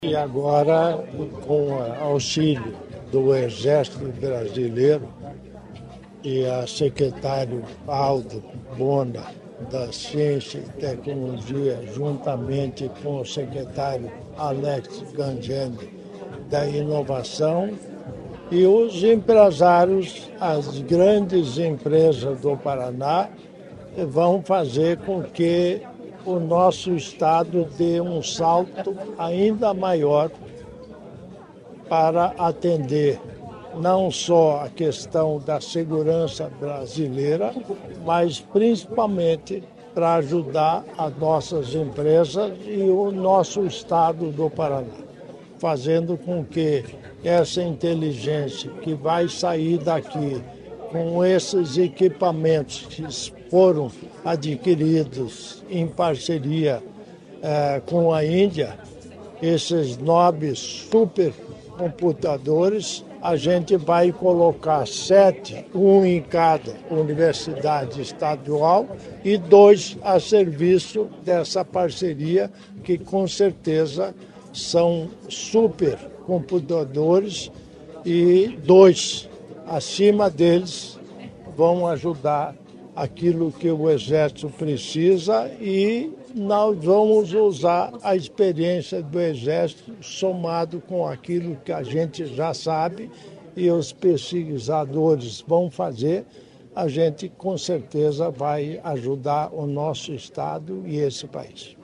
Sonora do vice-governador Darci Piana sobre a parceria com Exército para pesquisas com IA e cibersegurança